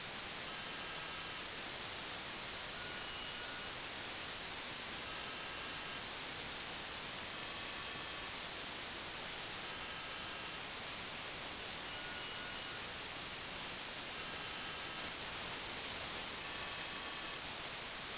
RAW IQ file You cannot overwrite this file.